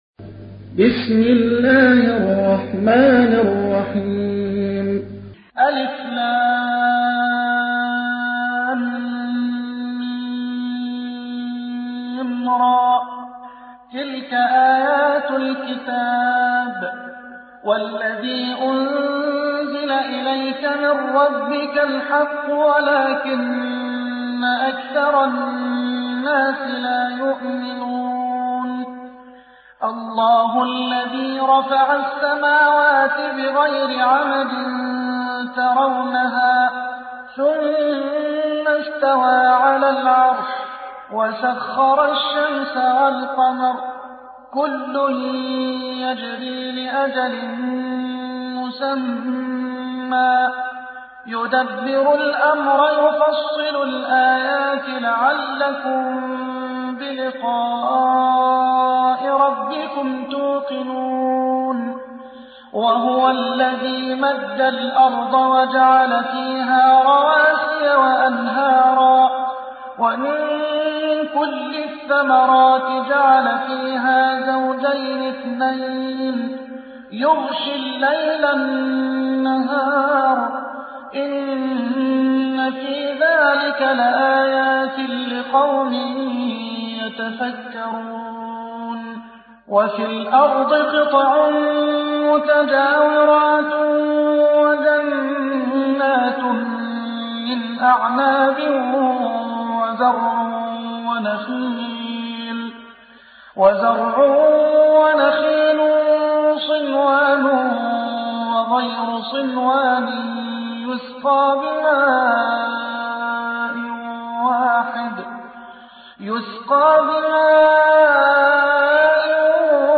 تحميل : 13. سورة الرعد / القارئ محمد حسان / القرآن الكريم / موقع يا حسين